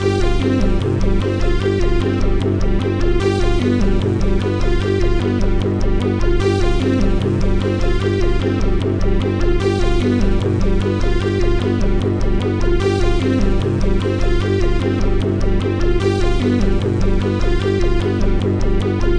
(gamerip)